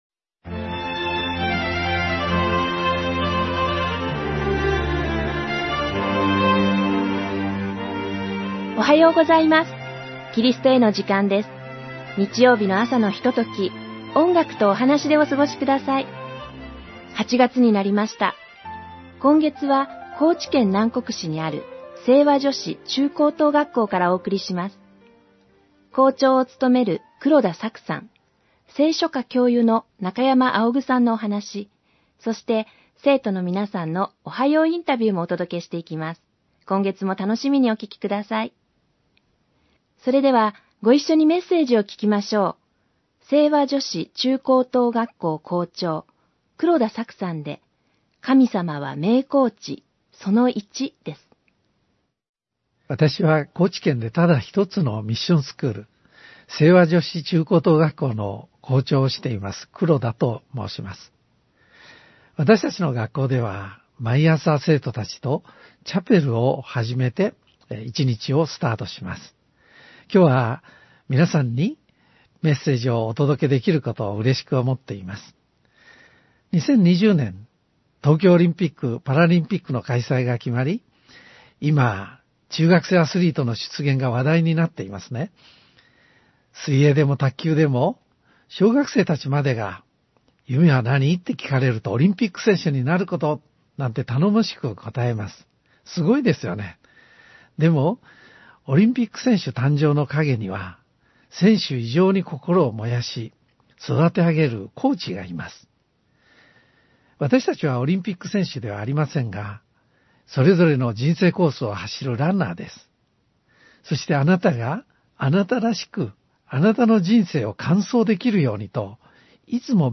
※ホームページでは音楽著作権の関係上、一部をカットして放送しています。